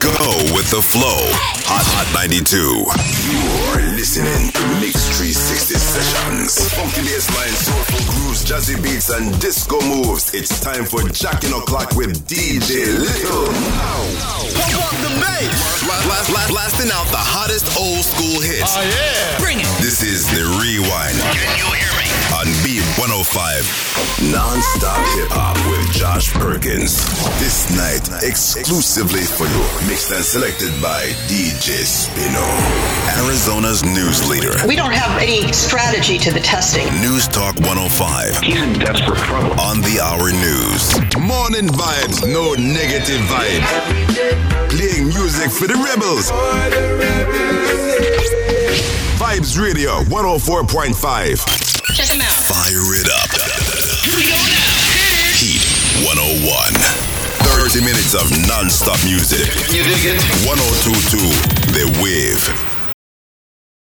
Male
Approachable, Assured, Authoritative, Confident, Conversational, Cool, Corporate, Deep, Engaging, Friendly, Gravitas, Natural, Reassuring, Smooth, Soft, Streetwise, Upbeat, Warm
American Southern, African American Vernacular English, Jamaican patois, UK (South London), New Yorker
Microphone: TLM103/MKH416